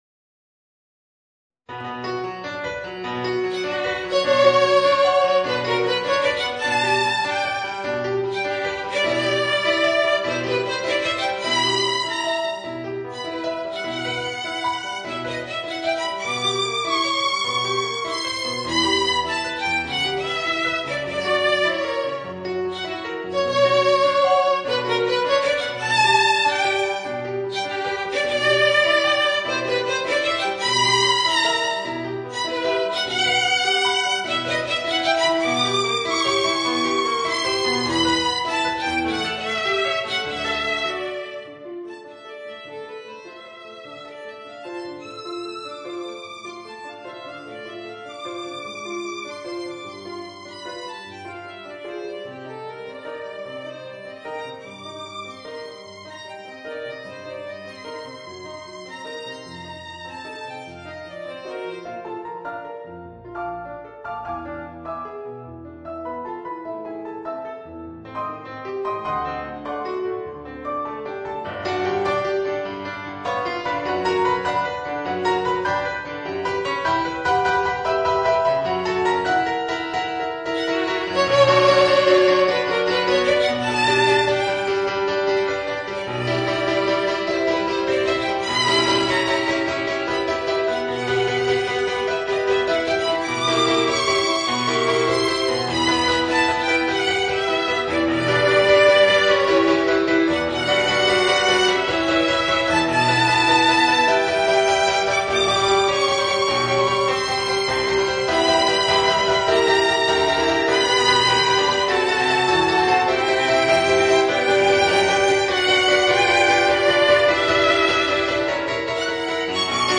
4 Trombones et Orgue